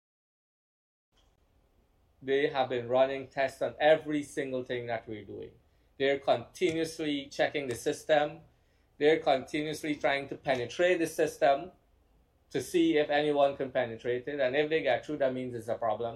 This assurance was recently provided by Minister of Public Service and Government Efficiency, Zulfikar Ally, when he spoke at a public meeting in Region Six over the weekend.